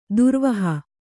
♪ durvaha